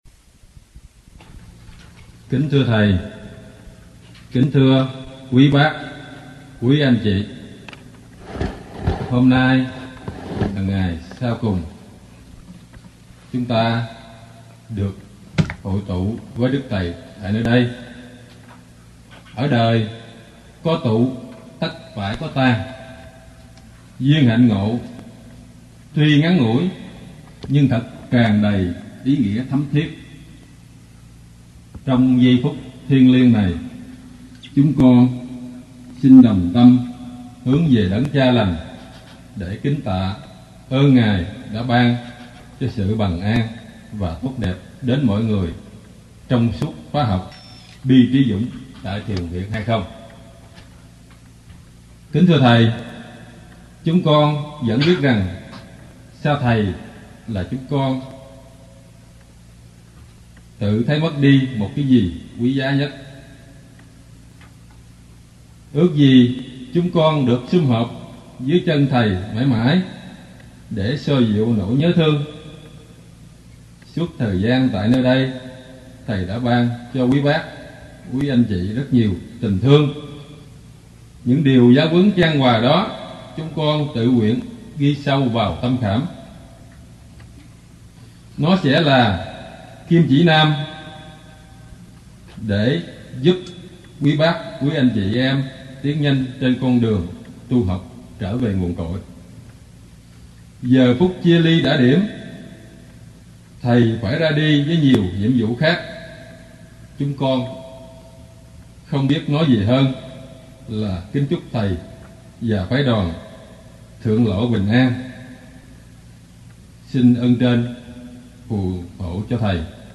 1986-06-10 - TV HAI KHÔNG - KHÓA BI TRÍ DŨNG 8 - LỄ BẾ MẠC